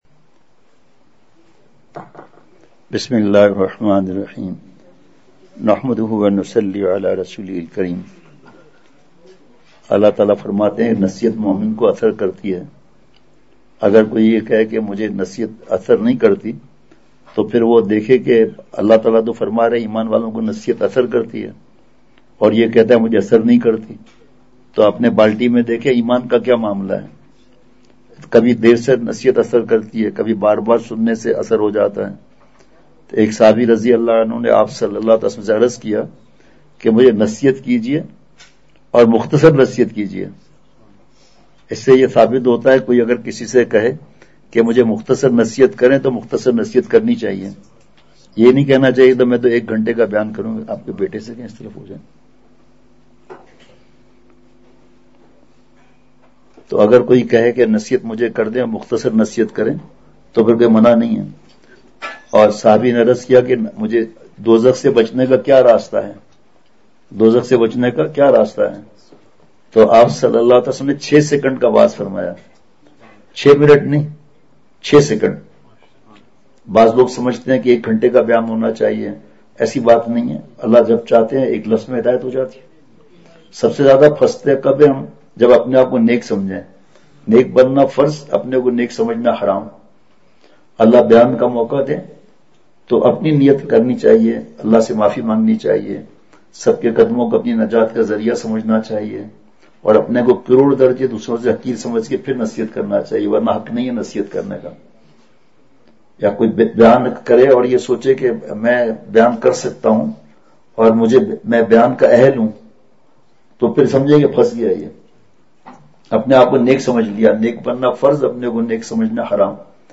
سفر پنجاب مجلس بعد عشاء ۲۴ / اکتوبر ۲۵ء:ریا کی حقیقت !
بمقام۔رہائش گاہ ملٹی گارڈن بی ۱۷ اسلام آباد